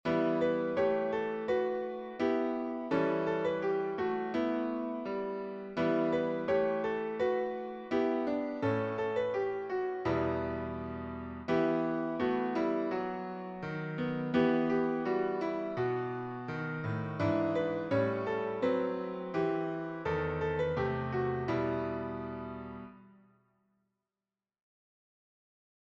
Medieval French carol